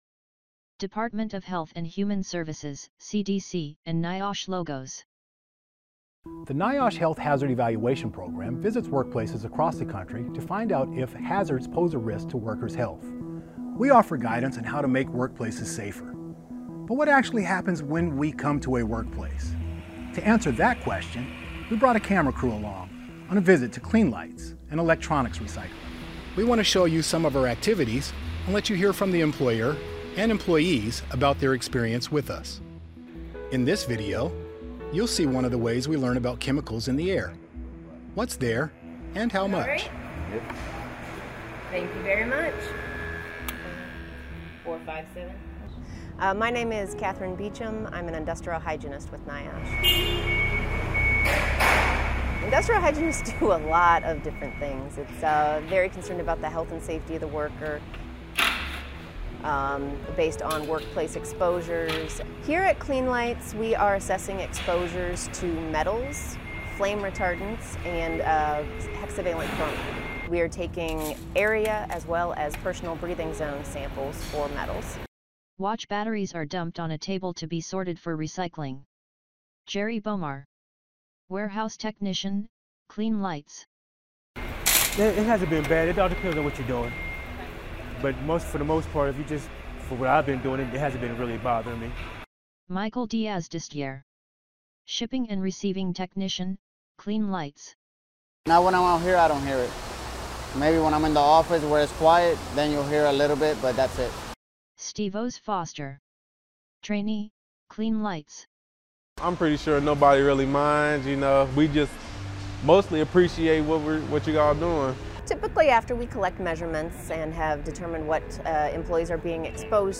HHE-Measuring-Contaminants-in-the-Air-at-a-Workplace_3.8.23_AudioDescripton.mp3